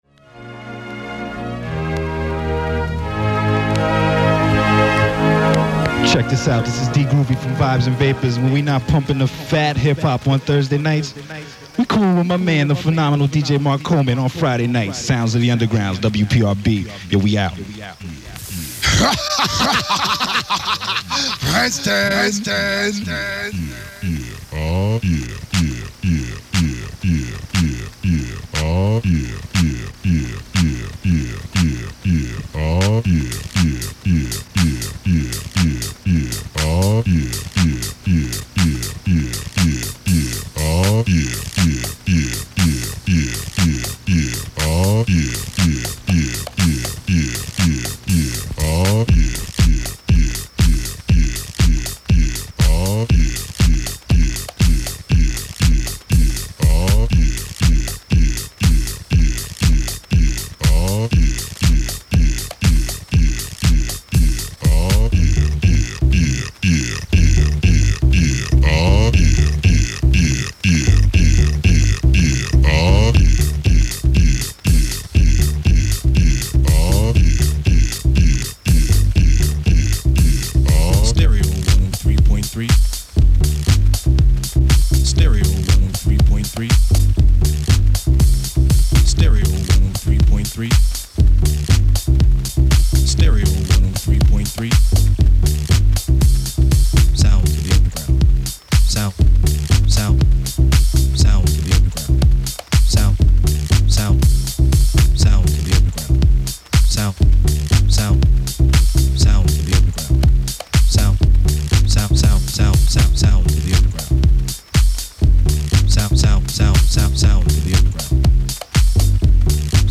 - Legendary 1990's Hip Hop Radio Show on WPRB 103.3 fm
Often busted mixes, long pauses…but not this time.
Also people wonder why I leave the commercials in.
Peep the Ford “In-Armrest” Cellular Phone commercial.